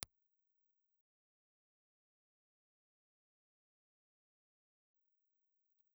Impulse Response file of the National VM-11 ribbon microphone.
National_VM11_IR.wav